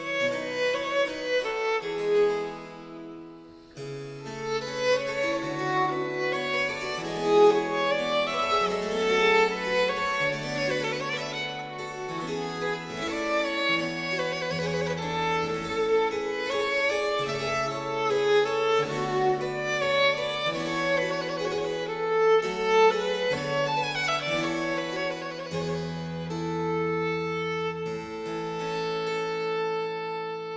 si bémol majeur